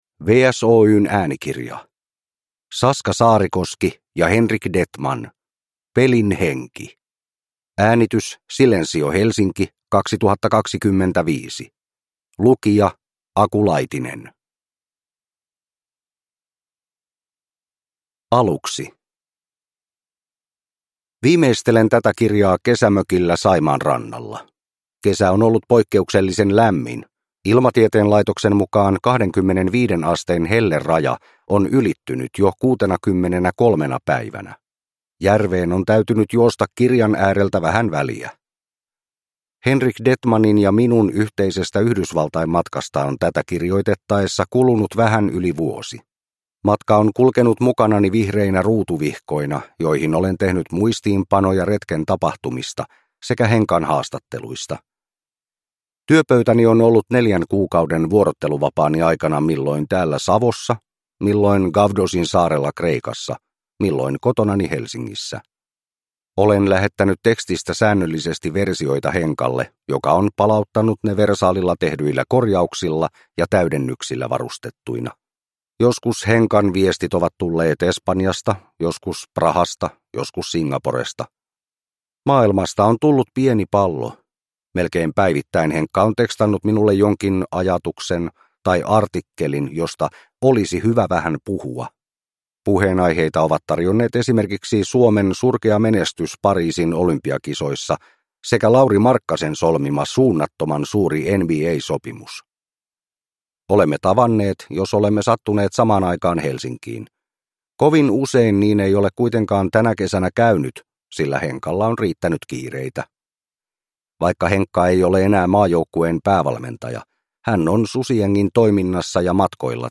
Pelin henki – Ljudbok